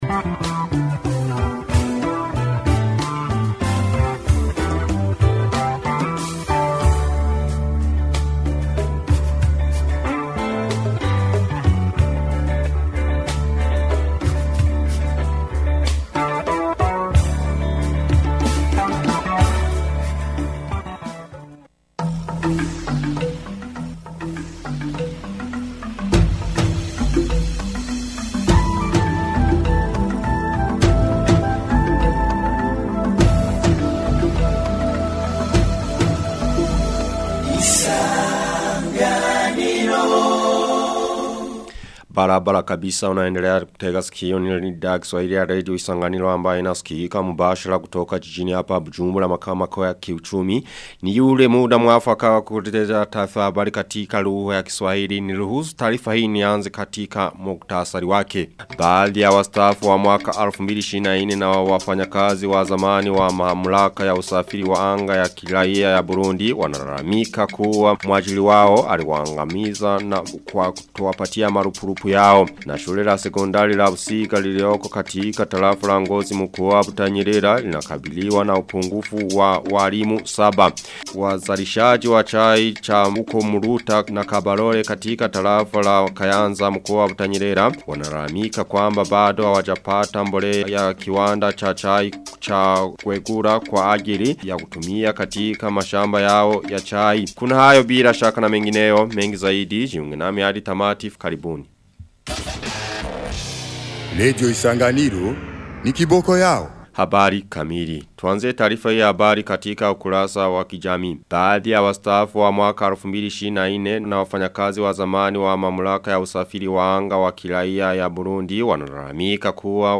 Taarifa ya habari 16 Januari 2026